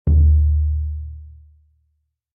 surdo-1.mp3